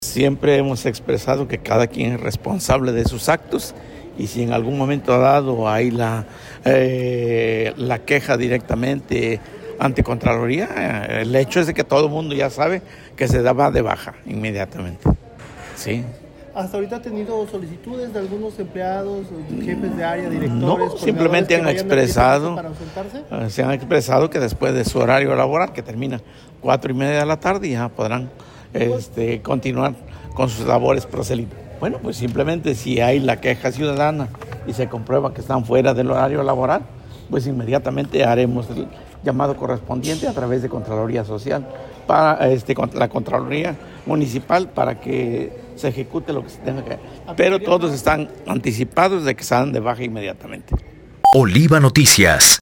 Entrevistado al finalizar el Cabildo abierto, el edil lanzó una advertencia clara a los funcionarios públicos del Ayuntamiento: cualquier trabajador que realice actos proselitistas durante su horario laboral será dado de baja de manera inmediata.